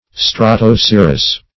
Search Result for " strato-cirrus" : The Collaborative International Dictionary of English v.0.48: Strato-cirrus \Stra`to-cir"rus\, n. [Stratus + cirrus.]